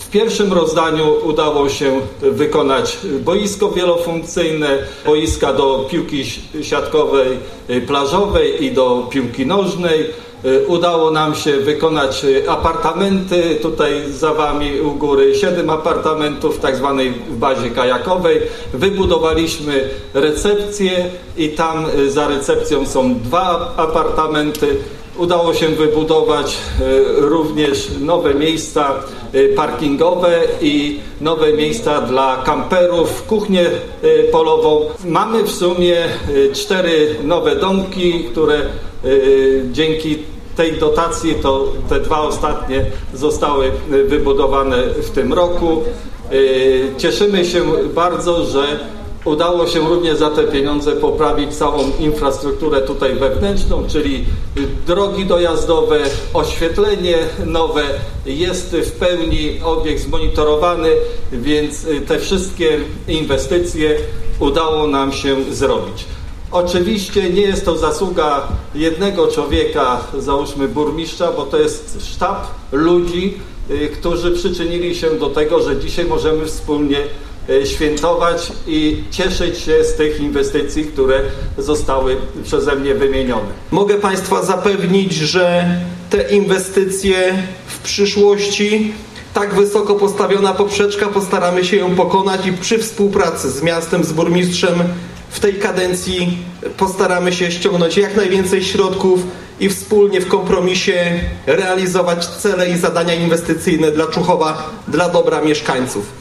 – W pierwszym rozdaniu udało się wykonać boisko wielofunkcyjne, boiska do piłki siatkowej plażowej i do piłki nożnej, udało się wykonać apartamenty w bazie kajakowej, wybudowaliśmy recepcję i tam za recepcją także są dwa apartamenty. Udało się wybudować także nowe miejsca parkingowe, nowe miejsca dla kamperów, kuchnię polową. Mamy też cztery nowe domki noclegowe – mówił podczas ceremonii otwarcia obiektów Burmistrz Człuchowa, Ryszard Szybajło.